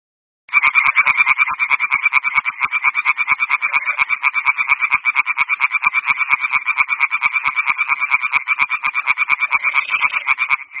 Raganella - Oasi Valle del Brusà
Canto-raganella.mp3